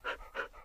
Minecraft Version Minecraft Version latest Latest Release | Latest Snapshot latest / assets / minecraft / sounds / mob / wolf / classic / panting.ogg Compare With Compare With Latest Release | Latest Snapshot
panting.ogg